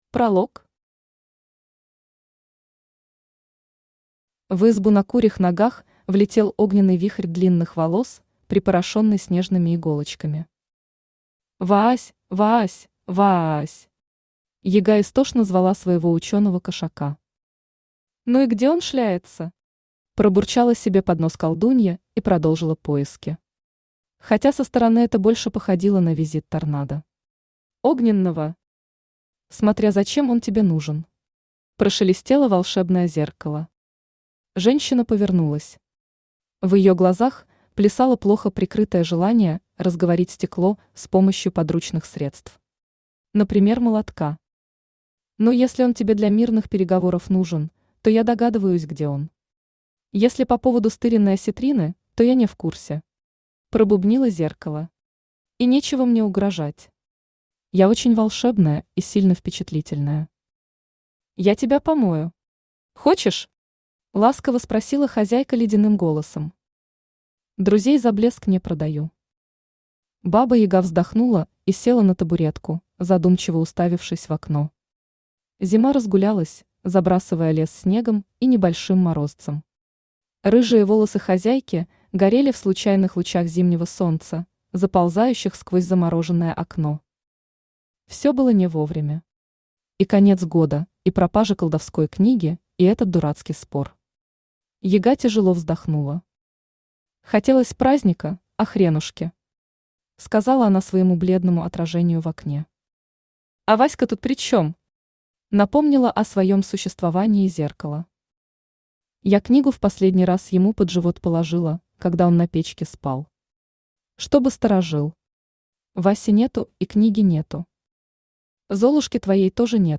Аудиокнига Баба Яга и Ко. Украденная колдовская книга | Библиотека аудиокниг
Украденная колдовская книга Автор Мария Юрьевна Фадеева Читает аудиокнигу Авточтец ЛитРес.